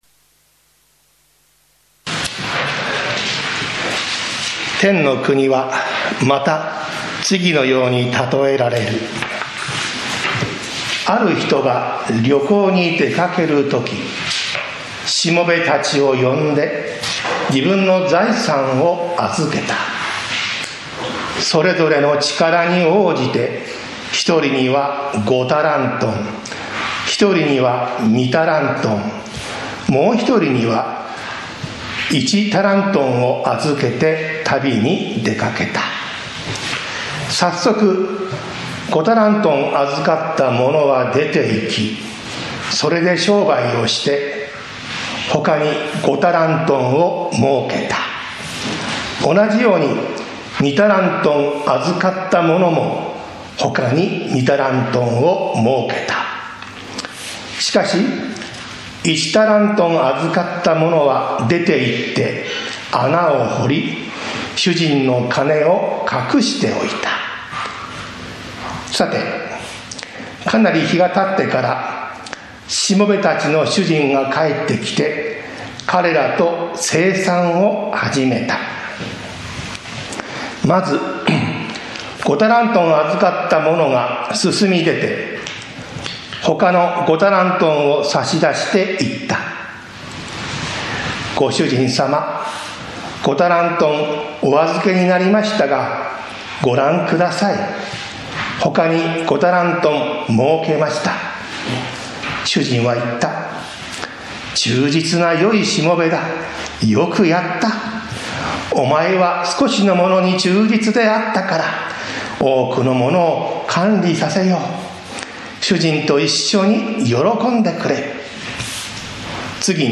タラントンの教え 宇都宮教会 礼拝説教